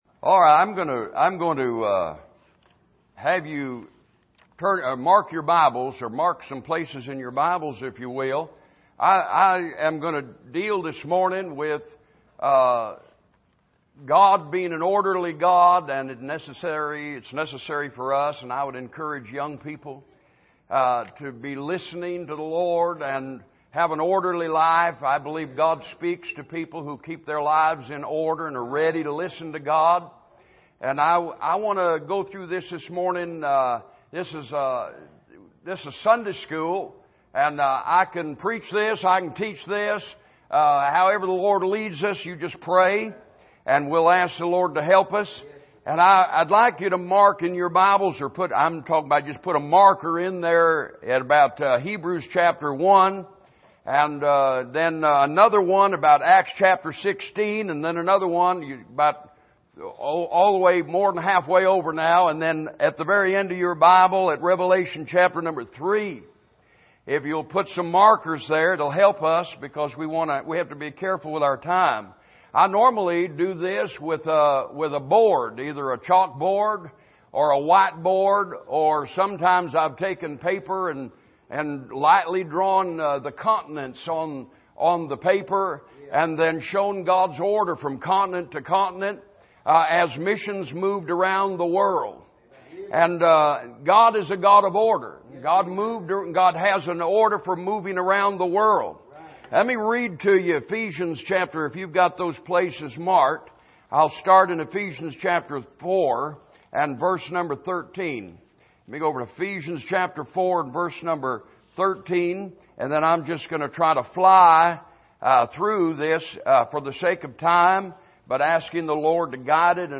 Series: 2019 Missions Conference Passage: Ephesians 4:12-13 Service: Sunday Morning God Is Orderly « Can God Trust You With Jesus?